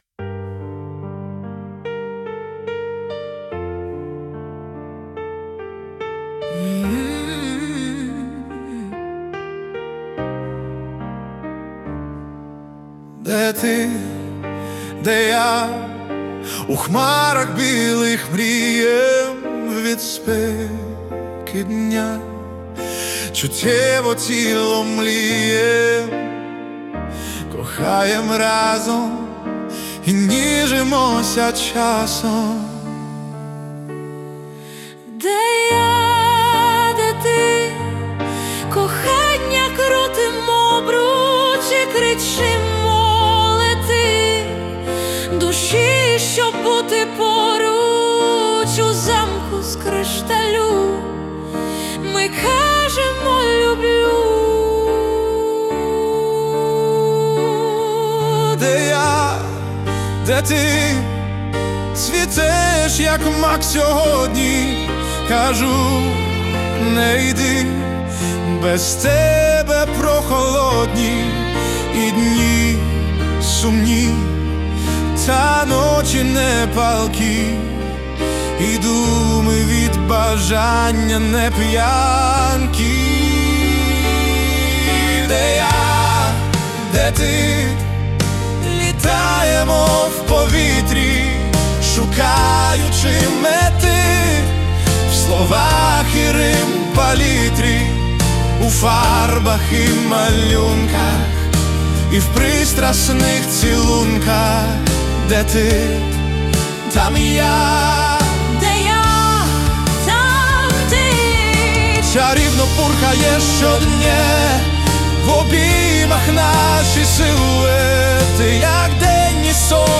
Музика і вокал ШІ - SUNO AI v4.5+
СТИЛЬОВІ ЖАНРИ: Ліричний
ВИД ТВОРУ: Пісня